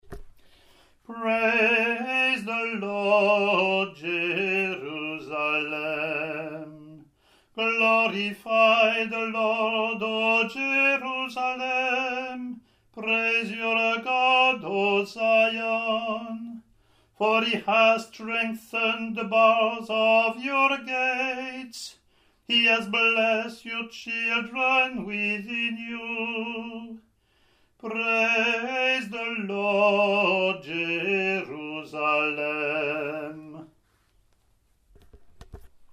The Roman Missal gives us these Bible verses to be sung per the examples recorded: the congregation joins the cantor for the antiphon (printed), then the cantor sing the Psalm alone, then the congregation and cantor repeat the antiphon.